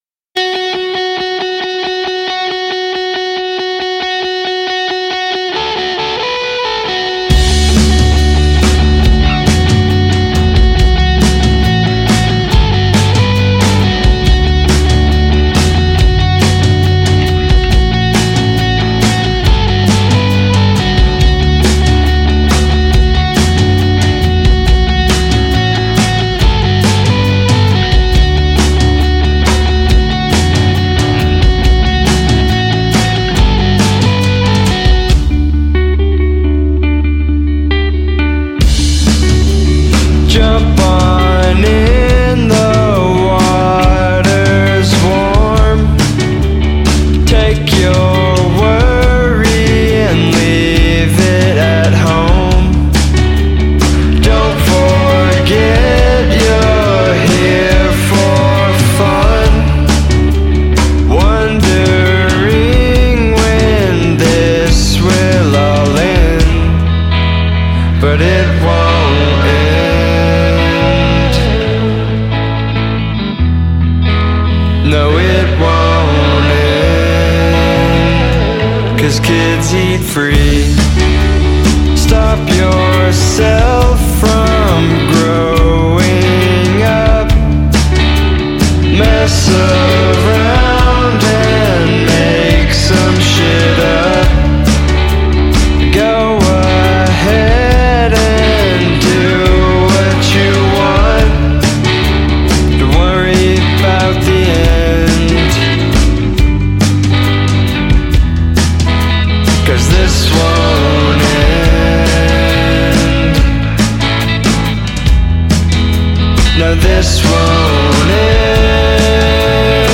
alternative rock three-piece